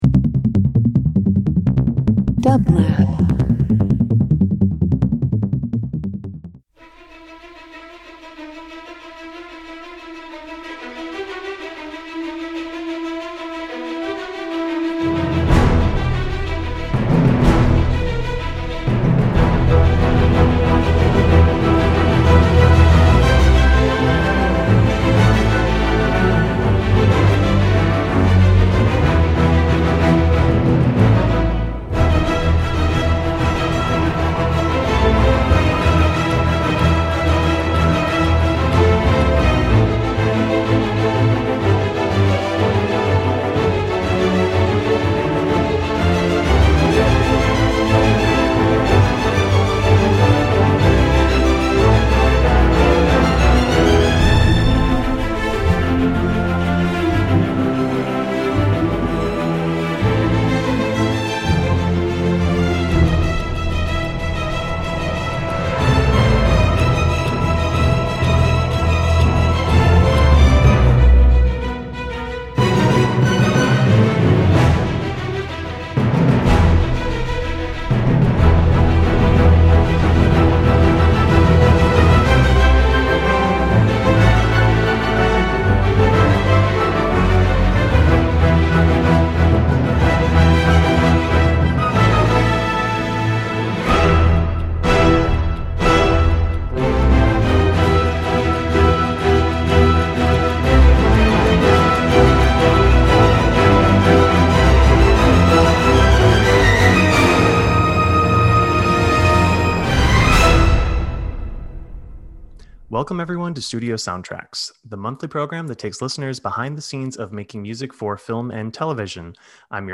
Studio Soundtracks takes listeners behind the scenes of how music is crafted for film and television by hearing directly from composers, songwriters and music professionals in the Entertainment Industry. Listen to inspiring conversations about composition and hear works from Emmy, Grammy, and Oscar-winning film scores on the show.